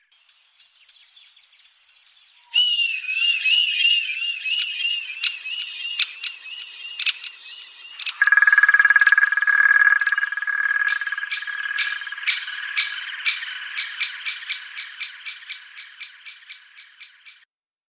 джунгли
Птицы которые обитают в Джунгляях